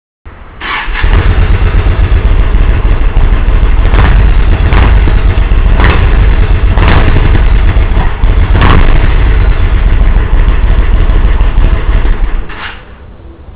ノーマルマフラーは、かな〜り静かに感じます。メカノイズのほうが大きいかもです。